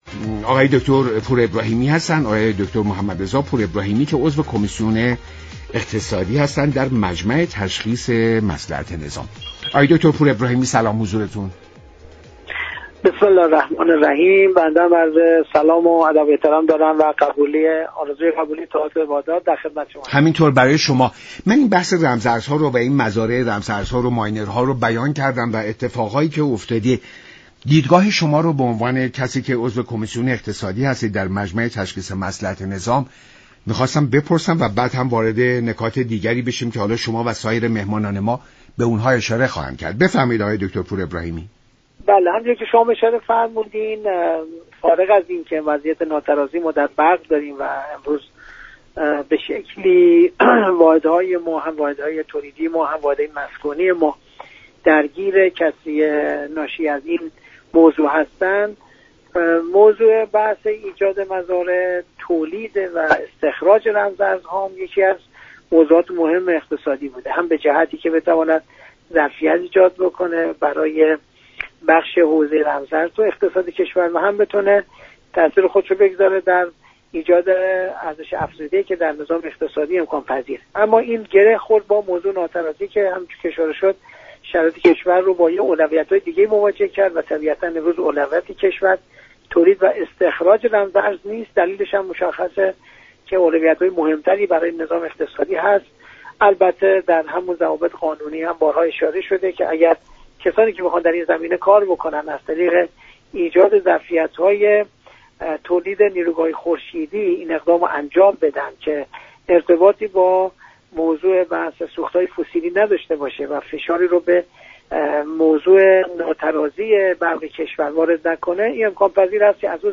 عضوكمیسیون اقتصادی مجلس در برنامه ایران امروز گفت: نیروگاه‌های خورشیدی از آن جهت كه جایگزین سوخت‌های فسیلی هستند ایجاد آنها نیاز به هیچ‌گونه مجوزی ندارد.